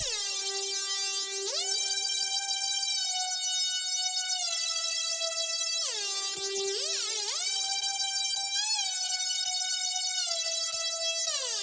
卡通 " 可爱的问号
描述：五个可爱的卡通声音，可用于突出某人头部的问号。听起来像柯比飞行时的噪音。你可以用它来吃声音，跳跃的声音，弹跳的声音......它适用于任何可爱的东西！ ;）制作Chiptone。
标签： 反弹 卡通 怪异 任天堂 动漫 跳跃 合成器 问题 飞行 柯比 可爱 视频游戏
声道立体声